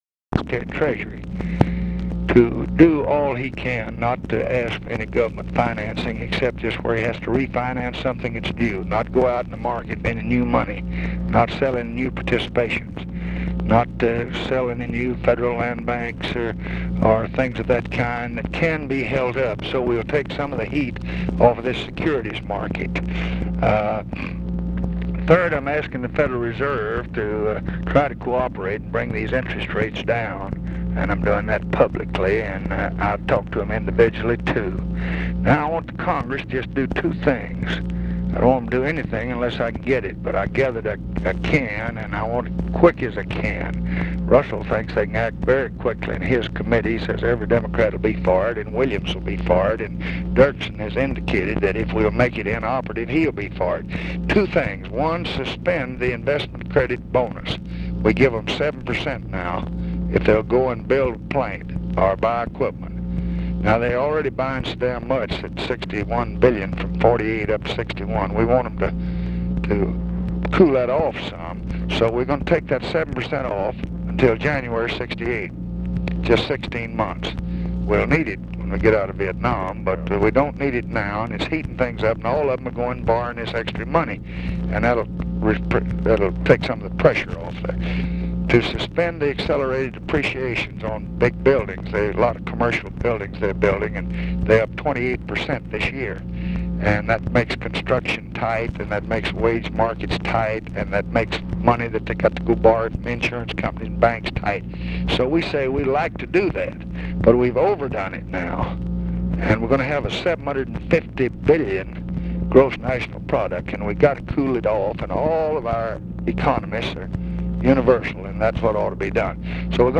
Conversation with MIKE MANSFIELD, September 8, 1966
Secret White House Tapes